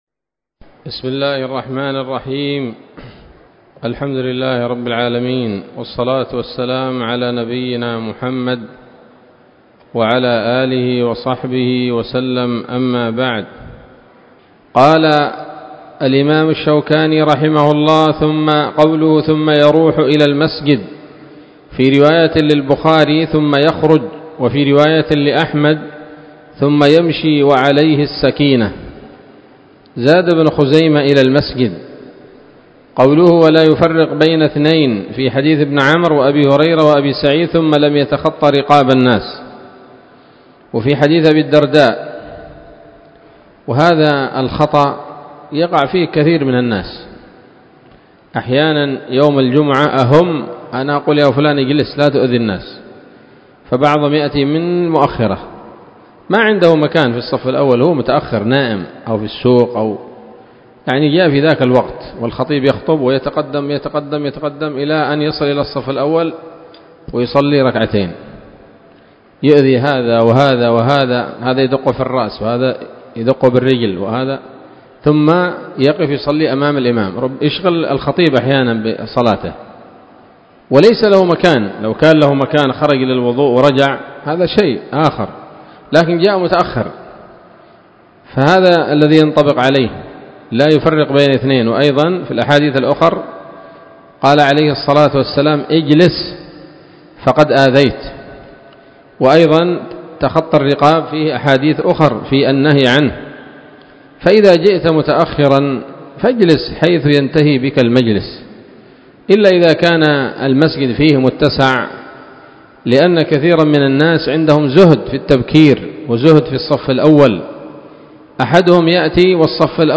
الدرس الثامن من ‌‌‌‌أَبْوَاب الجمعة من نيل الأوطار